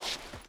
Stone Jump.ogg